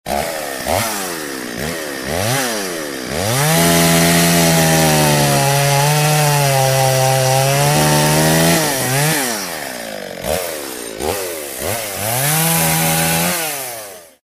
chainsaw-ringtone_14169.mp3